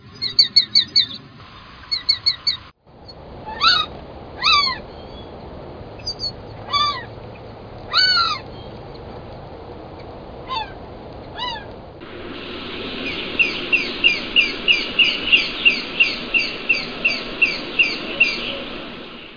Sharp-shinned Hawk
VOZ: Una rápida secuencia de agudas notas "kek".